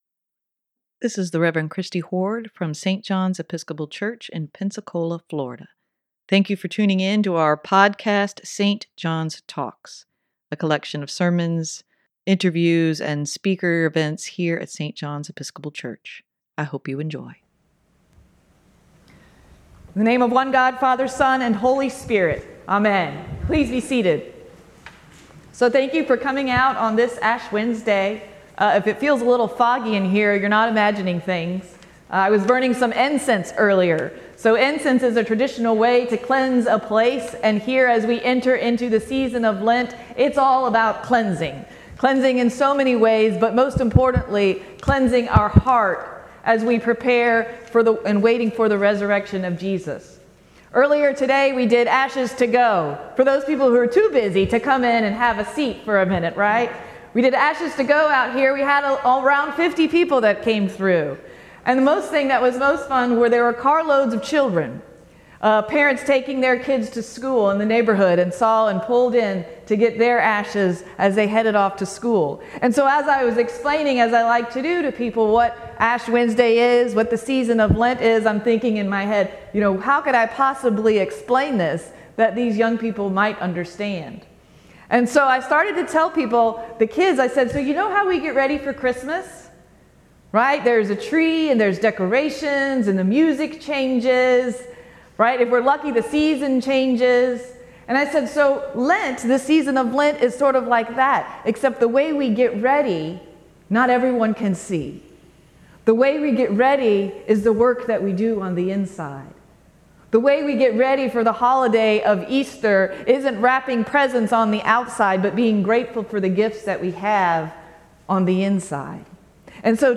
sermon-Ash-Wednesday-2023.mp3